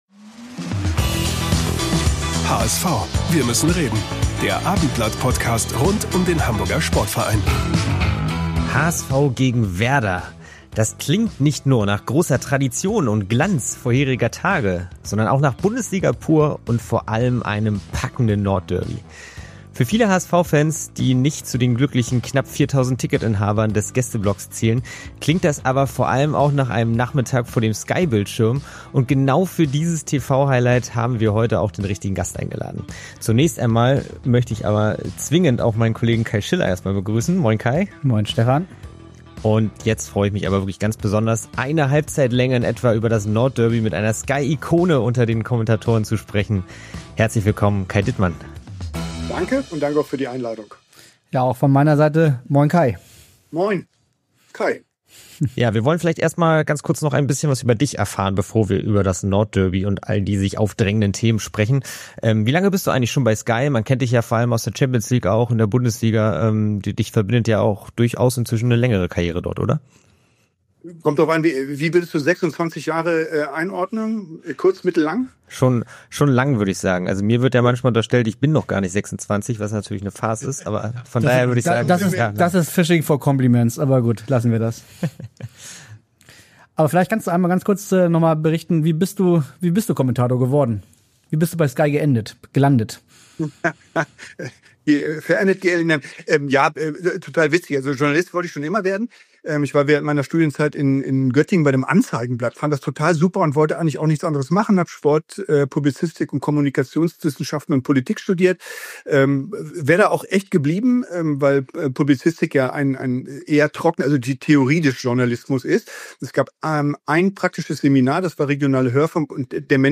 HSV-Transfer? Sky-Kommentator nennt Champions-League-Clubs für Vuskovic ~ HSV, wir müssen reden – der Fussball-Talk Podcast